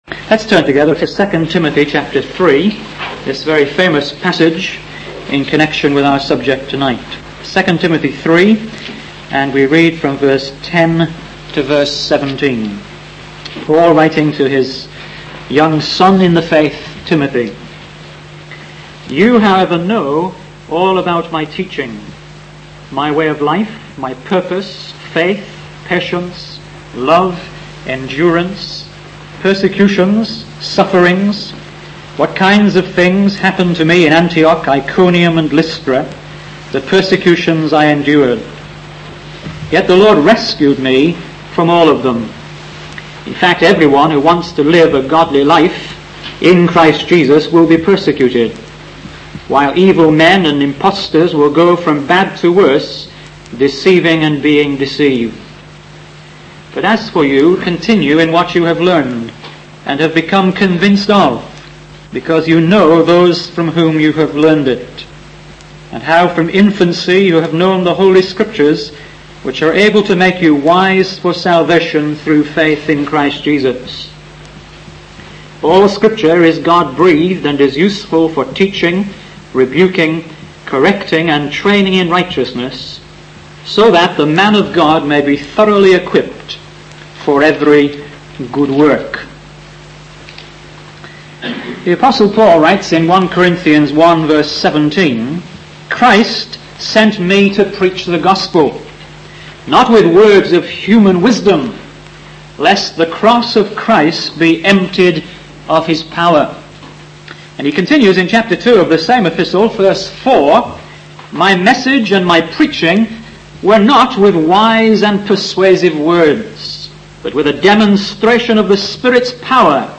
In this sermon, the preacher emphasizes the power and significance of the word of God.